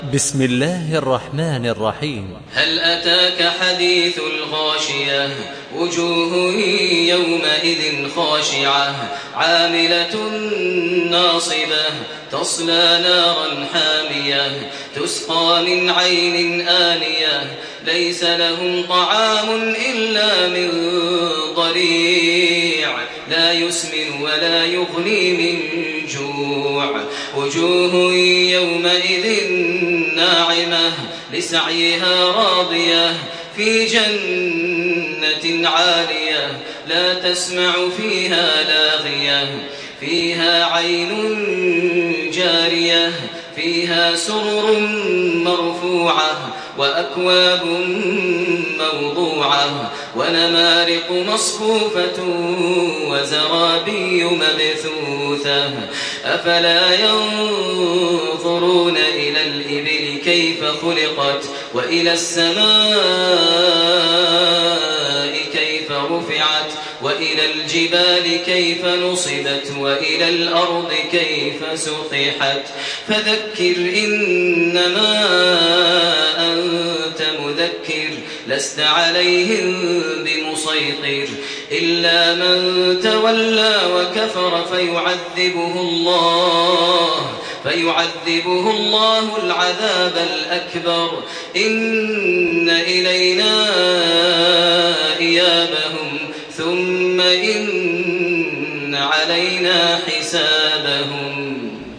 Surah Gaşiye MP3 by Makkah Taraweeh 1428 in Hafs An Asim narration.
Murattal Hafs An Asim